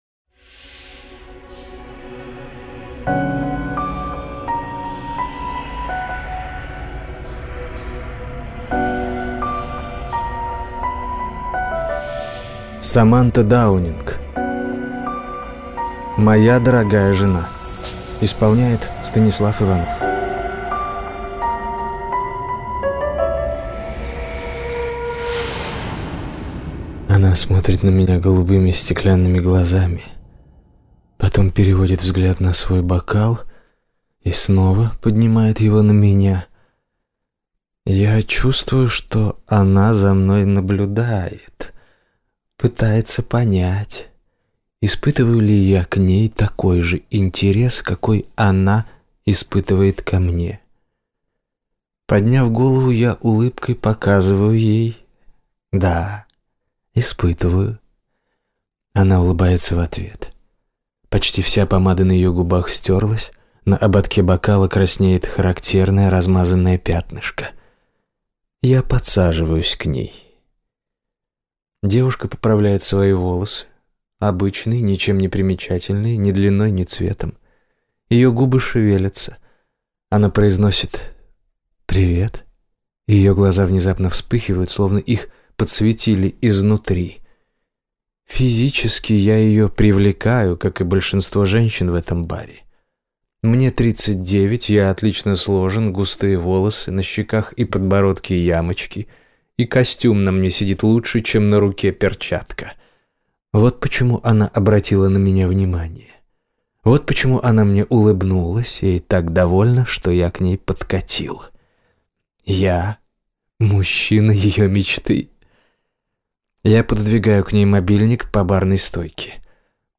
Аудиокнига Моя дорогая жена | Библиотека аудиокниг